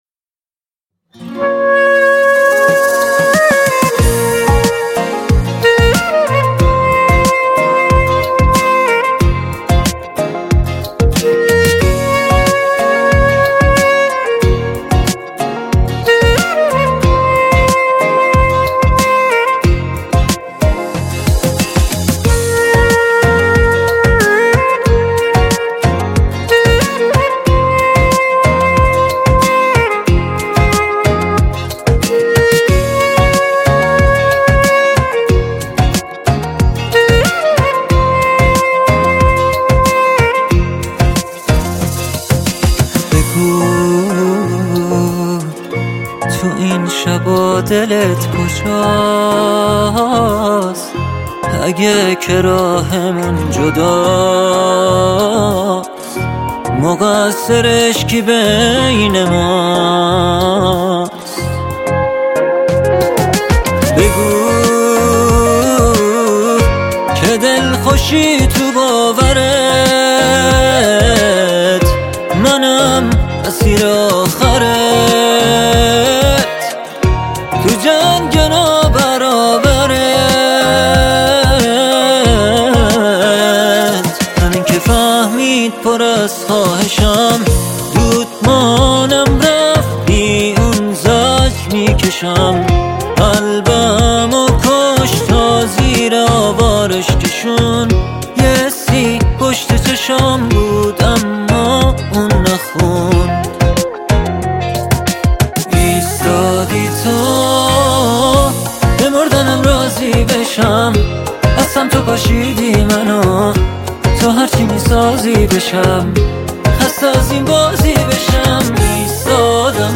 با ریتم 4/4